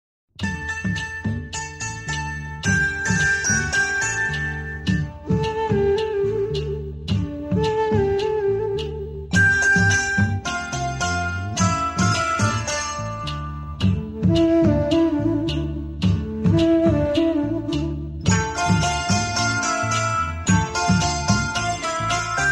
best flute ringtone download